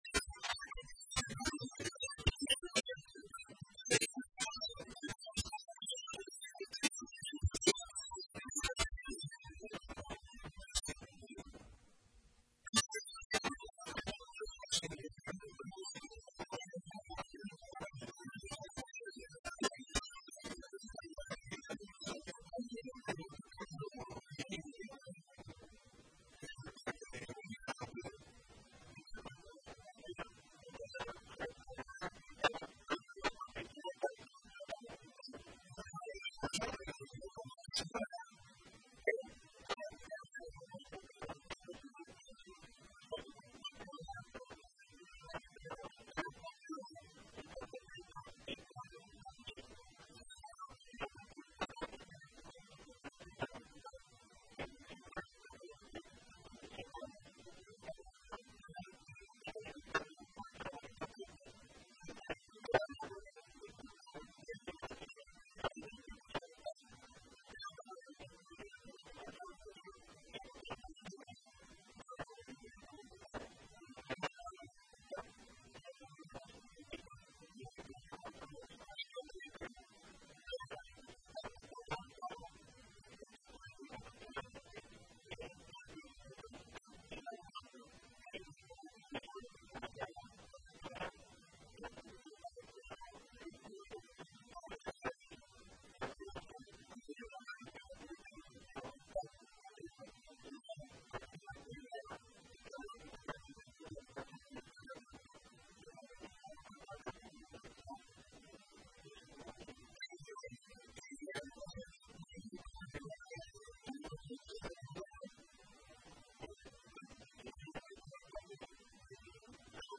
Conferencia de Prensa - Intendente Dr. Eduardo "Bali" Bucca